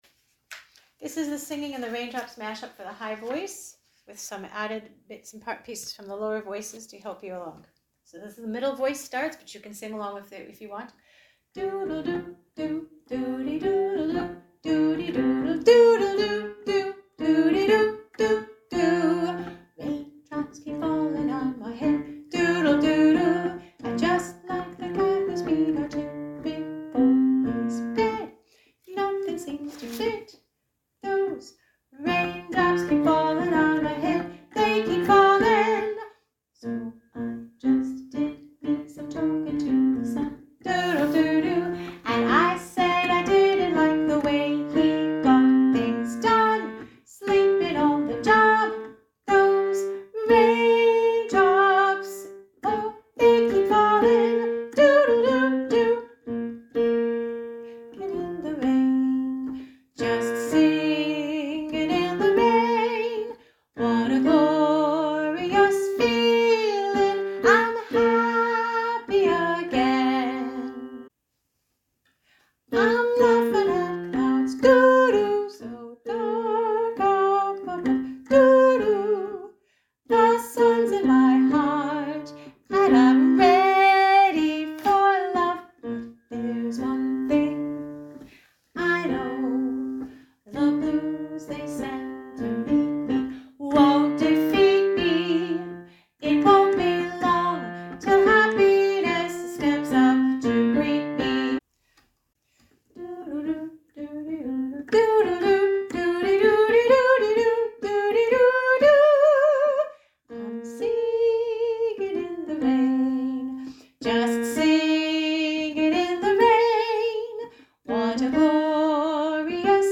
raindrops-mashup-upper-voice.mp3